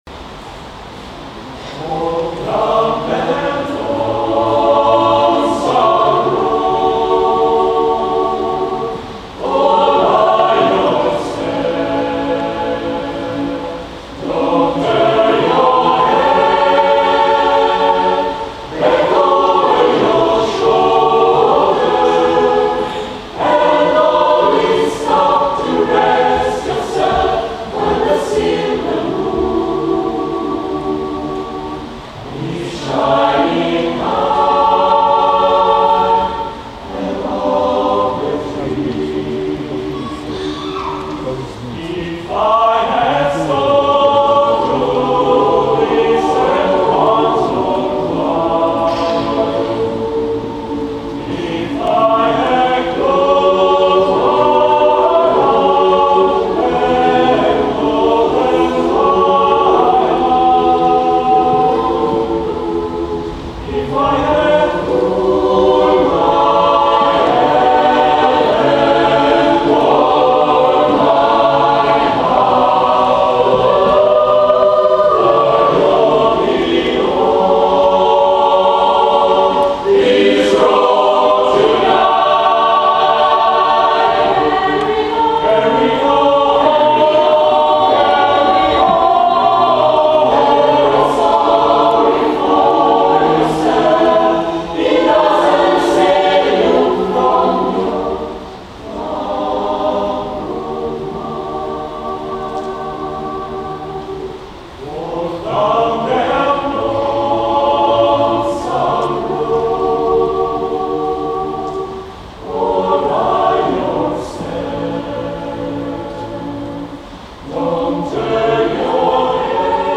XIV Rassegna corale al tendastrisce
(con sottofondo piovoso)   Pero' mi vuole bene (video) (audio)